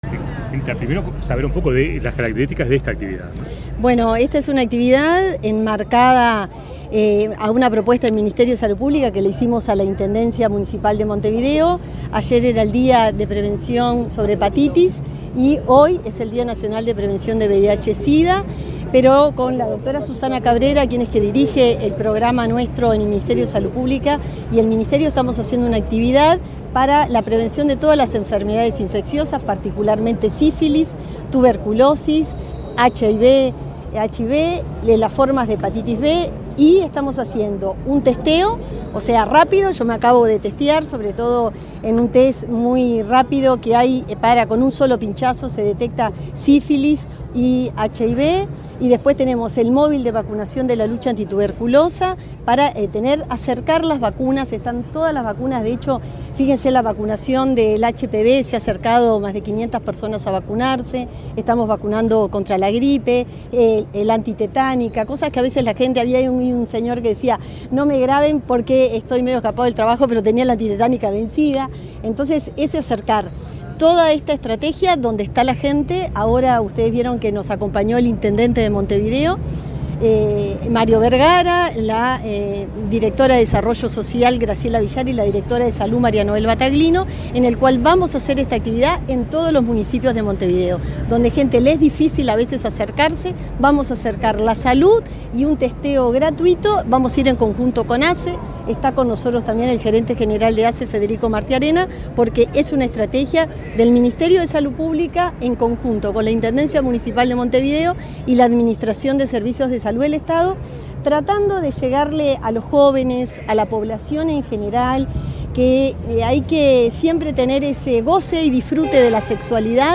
Declaraciones de la ministra de Salud Pública, Cristina Lustemberg
La titular del Ministerio de Salud Pública, Cristina Lustemberg, efectuó declaraciones a la prensa, luego de participar en una actividad de prevención